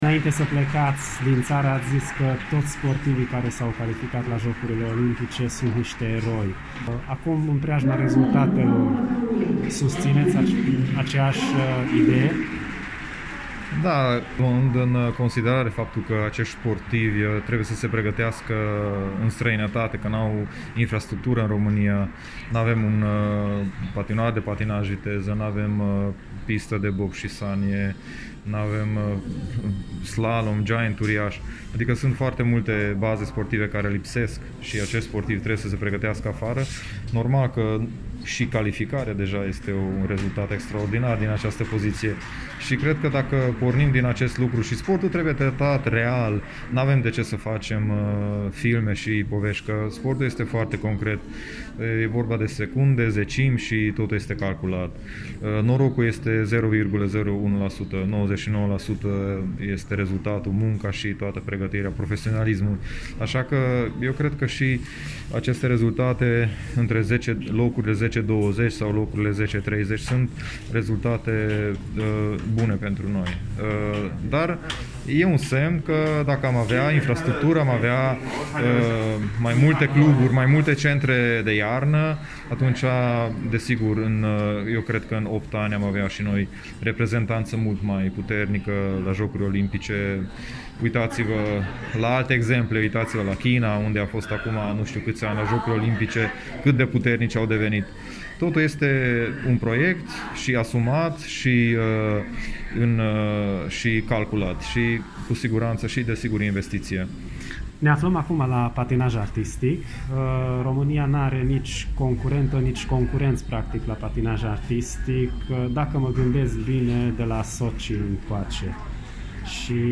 Un interviu cu Edurad Novak
Eduard-Novak-ministrul-sportului.mp3